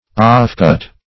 Offcut \Off"cut`\, n.